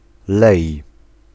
Ääntäminen
US : IPA : [ˈfə.nᵊl] UK : IPA : /ˈfʌn.əl/